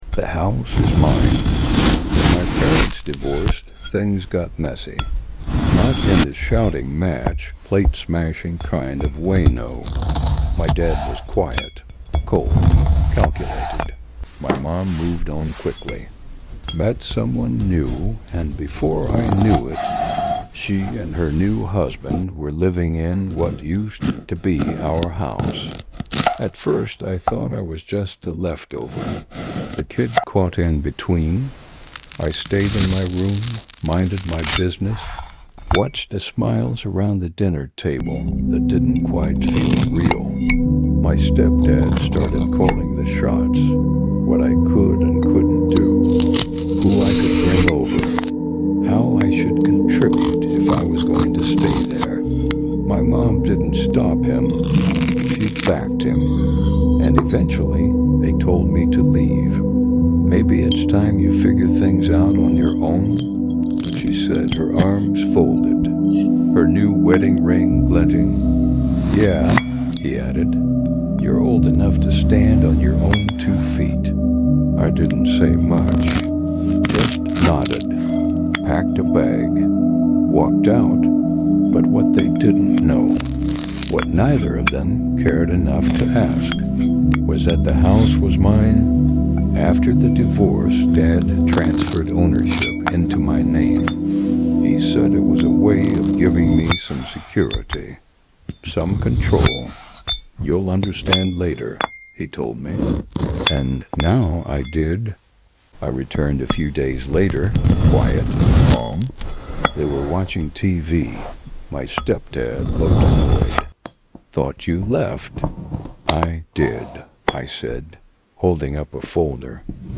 Story time with ai glass fruit cutting